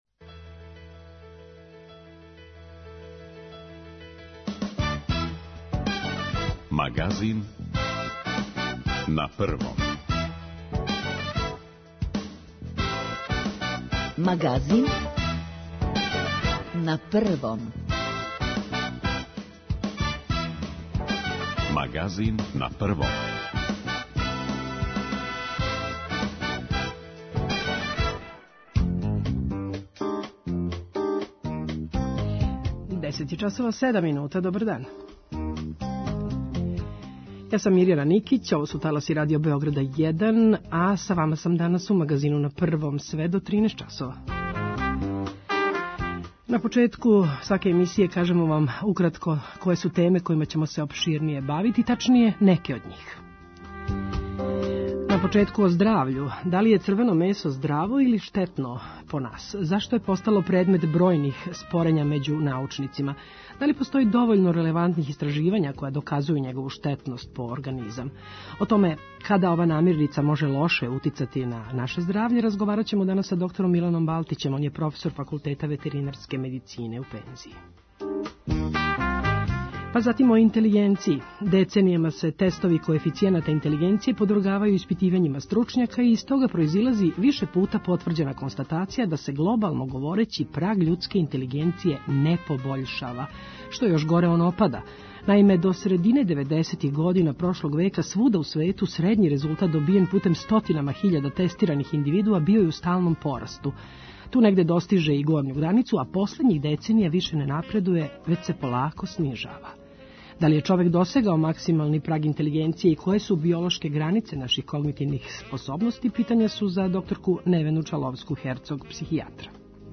10:05 -> 14:45 Извор: Радио Београд 1 Аутор